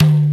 Bongo 5.wav